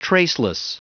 Prononciation du mot traceless en anglais (fichier audio)
Prononciation du mot : traceless